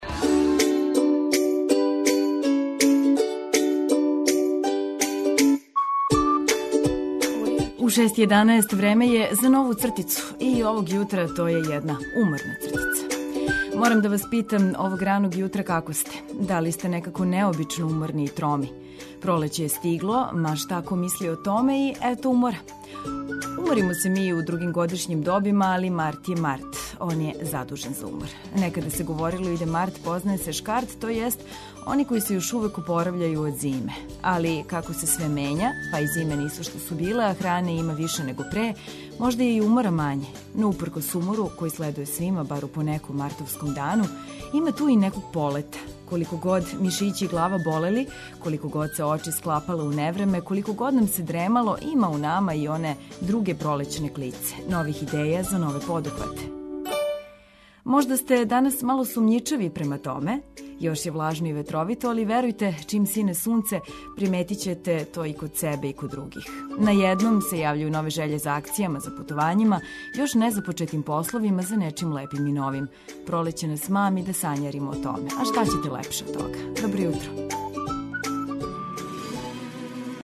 Разбудите се уз полетну музику и важне информације на таласима Београда 202!